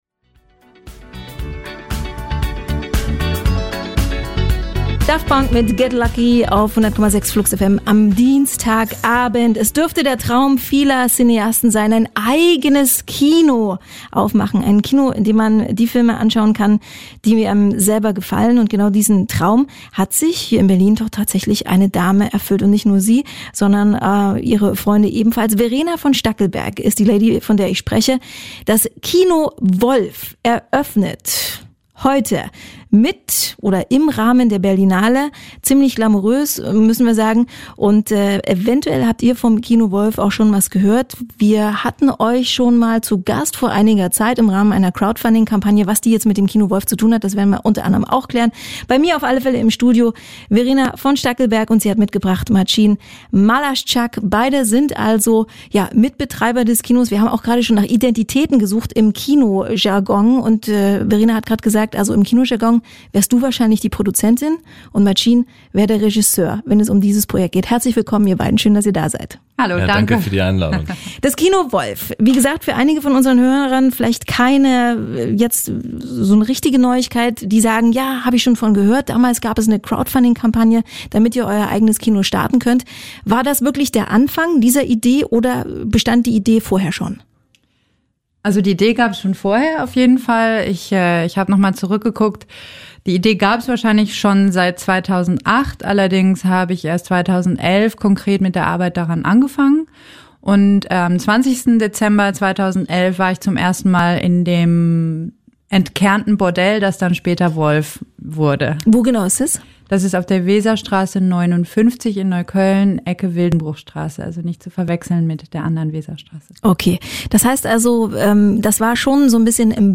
Das Interview gibt es hier nochmal in voller Länge zum Nachhören: http